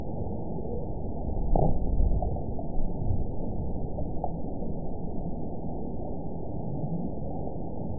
event 918031 date 04/27/23 time 08:22:12 GMT (2 years ago) score 9.38 location TSS-AB03 detected by nrw target species NRW annotations +NRW Spectrogram: Frequency (kHz) vs. Time (s) audio not available .wav